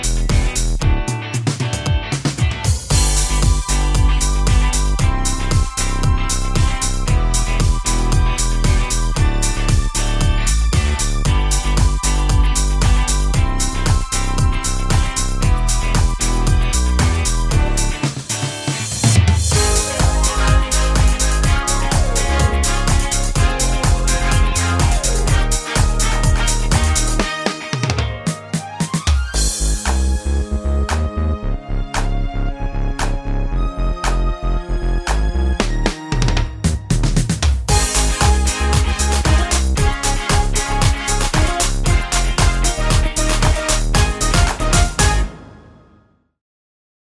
no Backing Vocals Comedy/Novelty 1:37 Buy £1.50